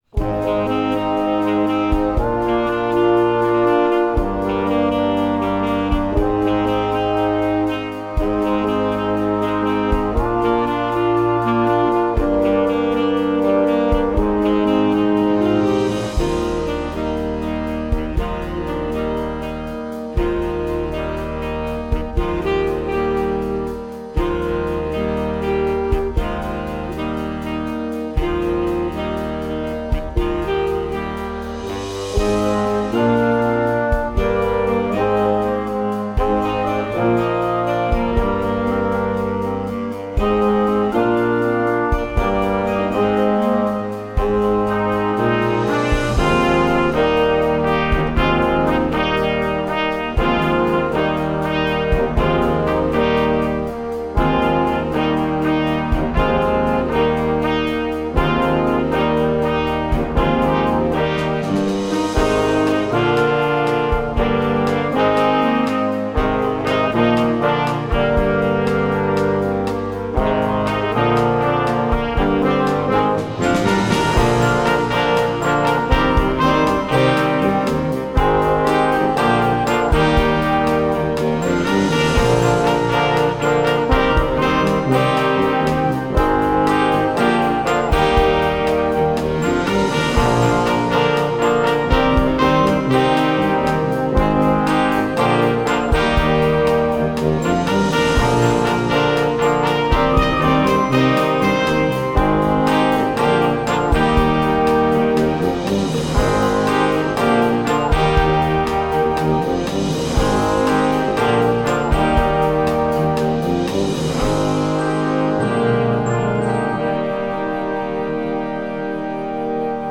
Gattung: für Jugendblasorchester
Besetzung: Blasorchester